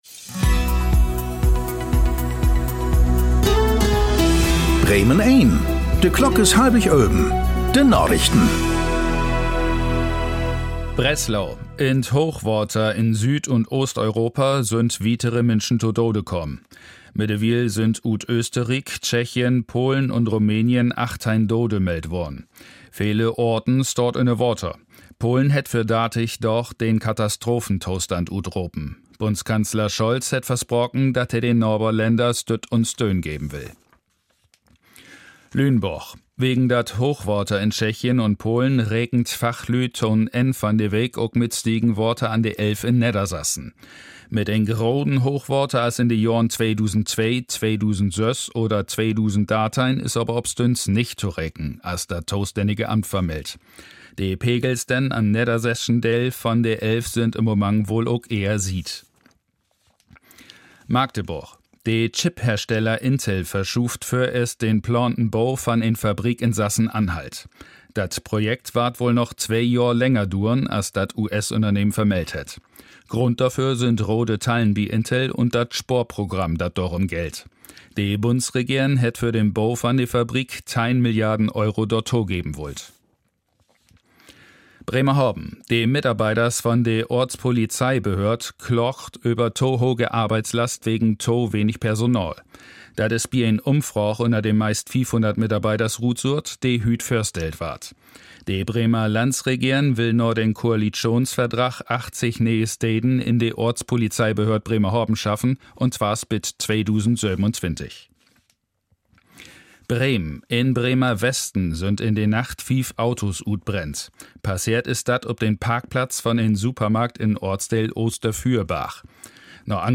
Plattdeutsche Nachrichten
Aktuelle plattdeutsche Nachrichten werktags auf Bremen Eins und hier für Sie zum Nachhören.